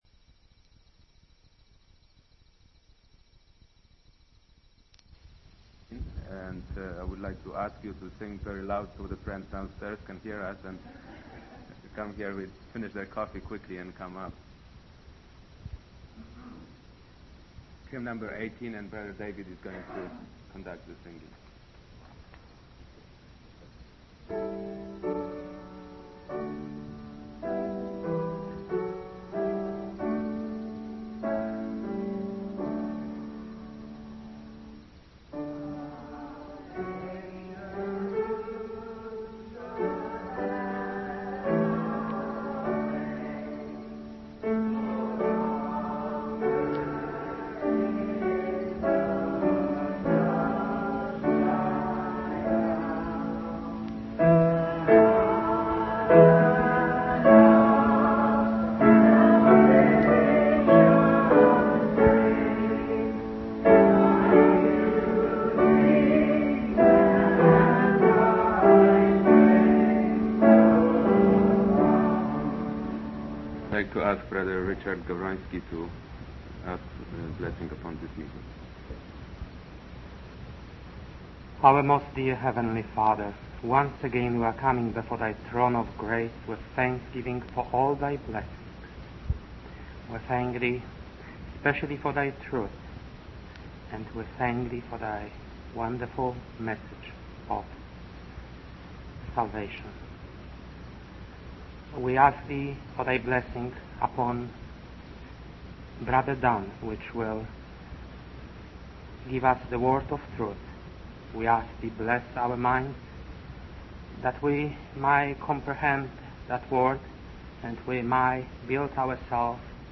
From Type: "Discourse"
Given at Vancouver, BC Convention